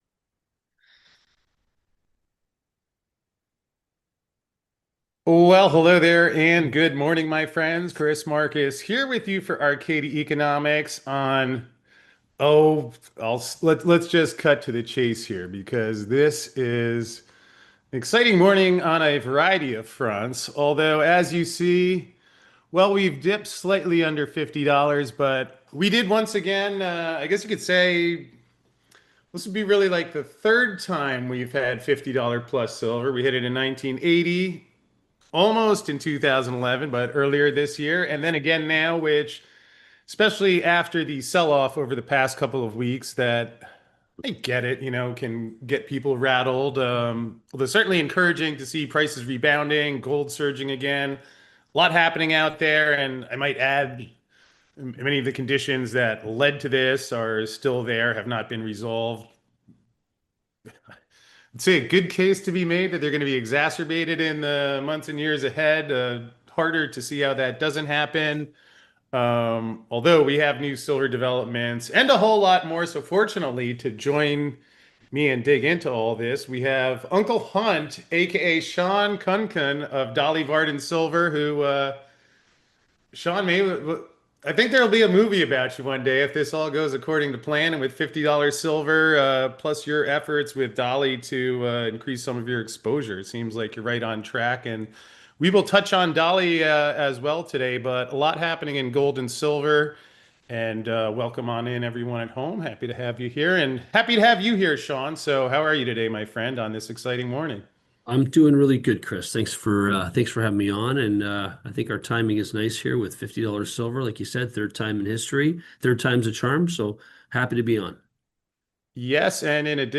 That means the volatility we saw last month is far from over, and to find out more about the latest developments in the gold and silver markets, join us for this live call at 11:00 a.m. Eastern!